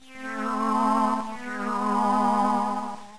PERISH_SONG.wav